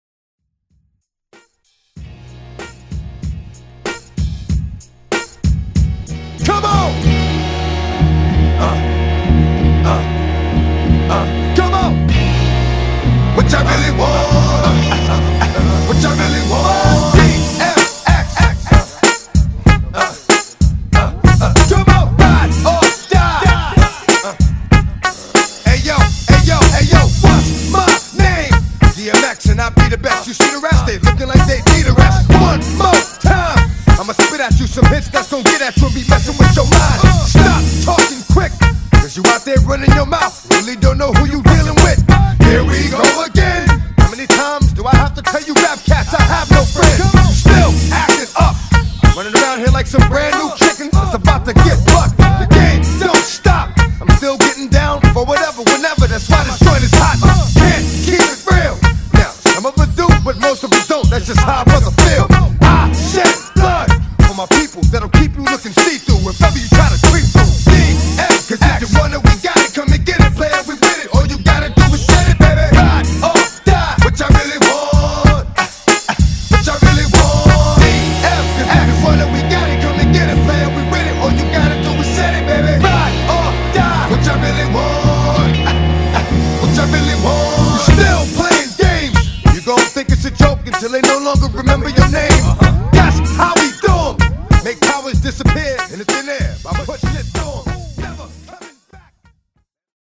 MASHUPS , ROCK , TOP40 Version: Clean BPM: 130 Time